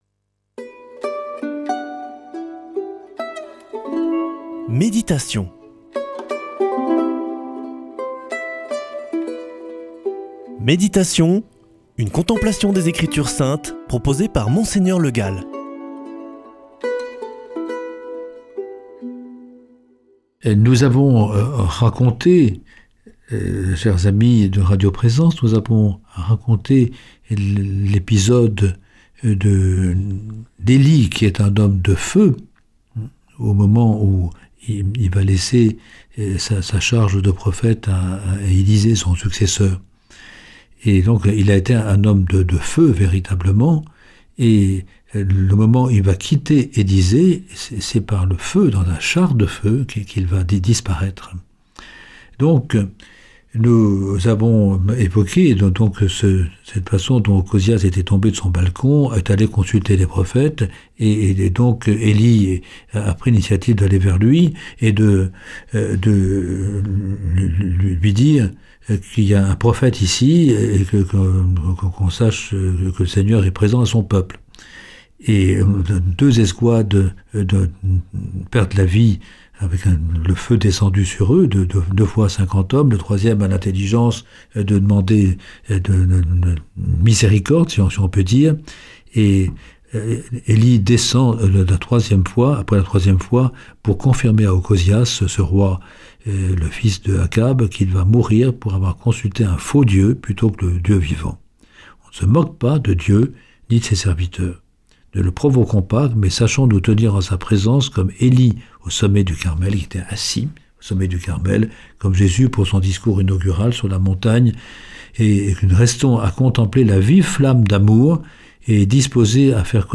Une émission présentée par
Présentateur